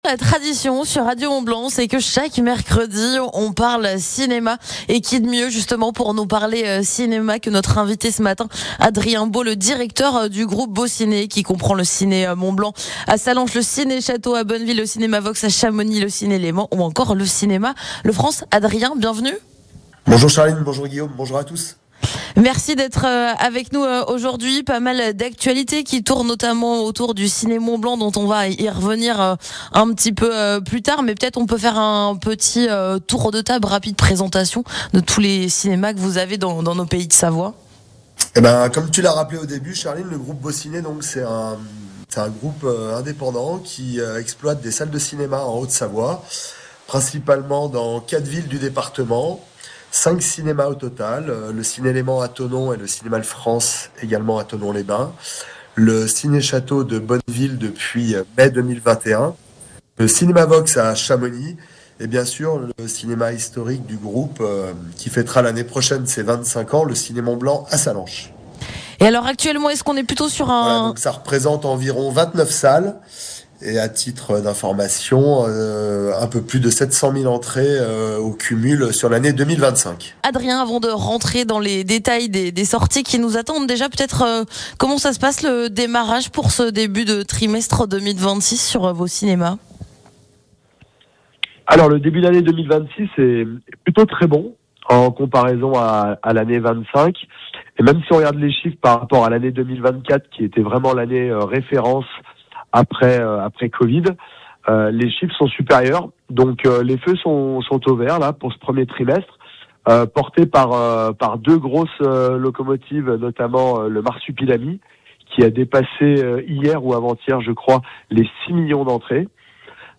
Interview part 1